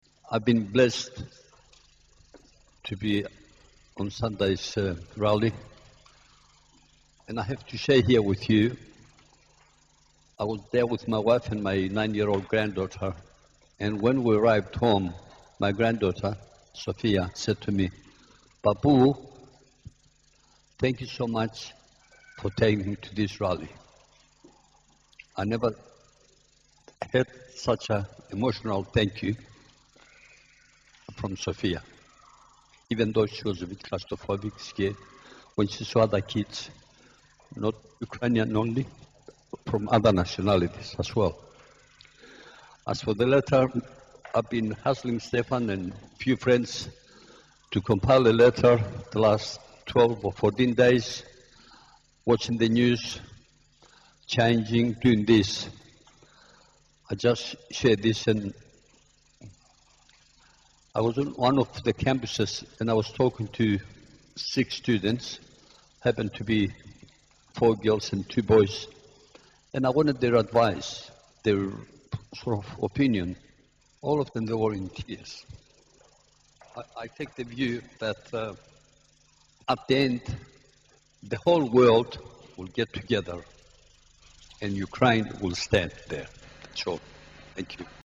Молебень за мир в Україні - 7
Ukrainian Catholic Cathedral of the Holy Apostles Peter and Paul.